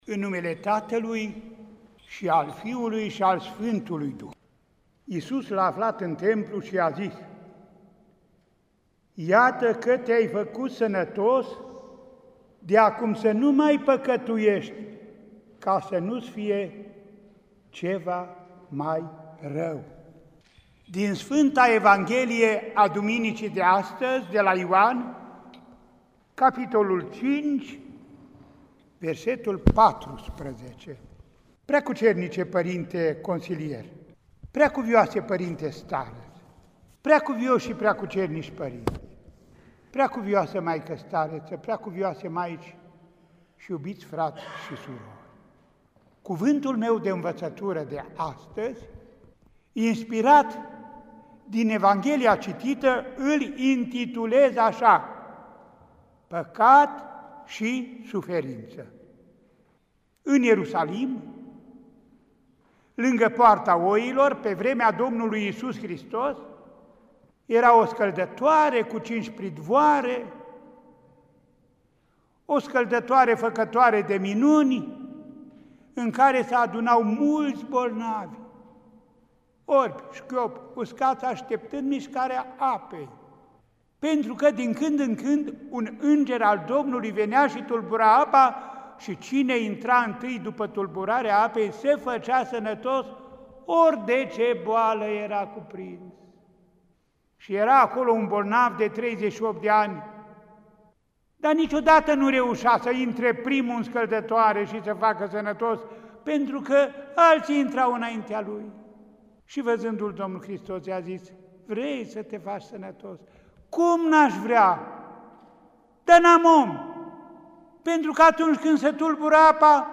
Cuvântul de învățătură al Înaltpreasfințitului Părinte Andrei, Arhiepiscopul Vadului, Feleacului și Clujului și Mitropolitul Clujului, Maramureșului și Sălajului, rostit în Duminica a IV-a după Paști (a vindecării slăbănogului de la Vitezda), 10 mai 2020, la Mănăstirea Rebra-Parva, județul Bistrița-Năsăud.